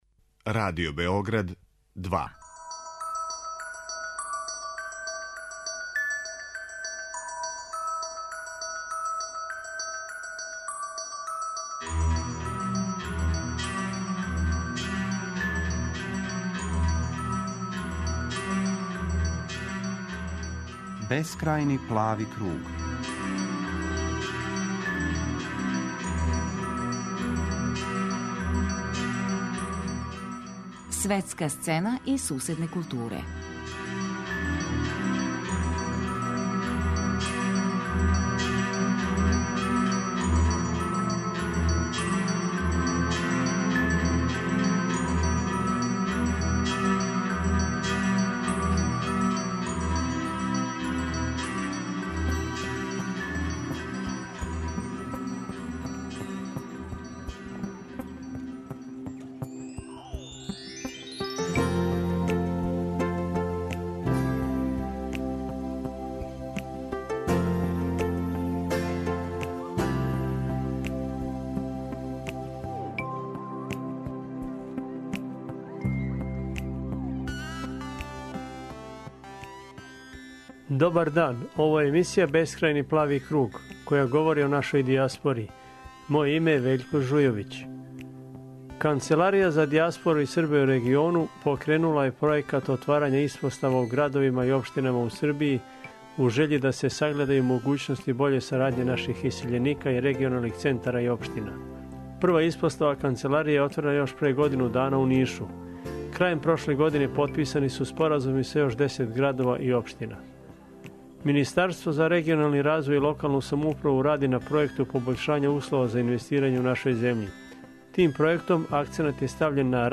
У данашњој емисији чућемо Игора Мировића, министра у оставци за Регионaлни развој и локалну самоуправу, проф. др Зорана Перишића, градоначелника Ниша и Братислава Гашића, градоначелника Крушевца, који ће говорити о могућностима сарадње матице и дијаспоре.